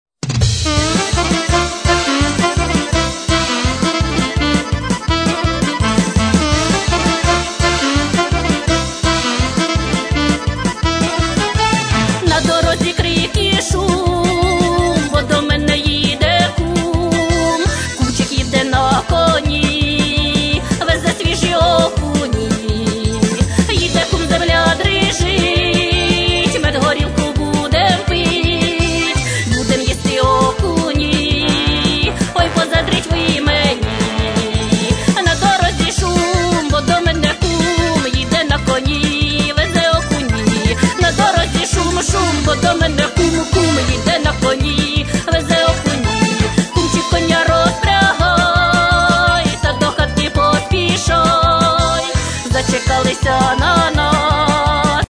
Збірка весільної музики